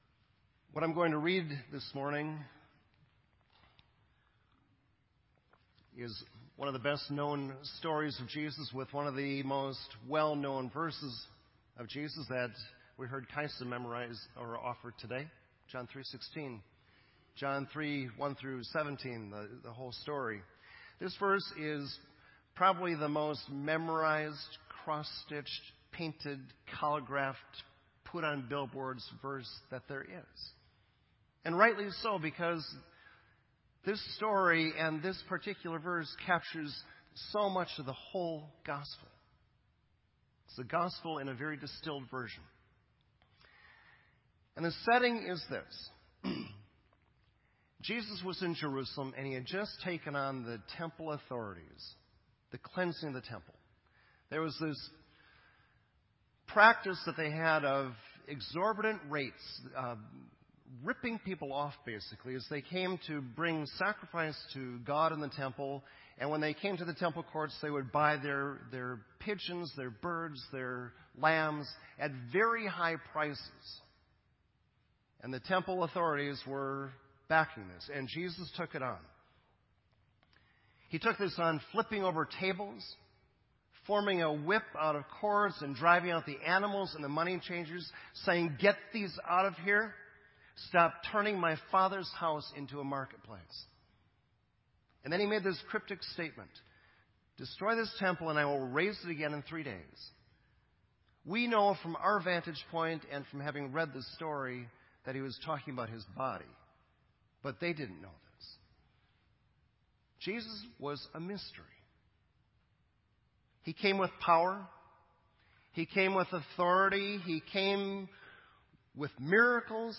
This entry was posted in Sermon Audio on June 1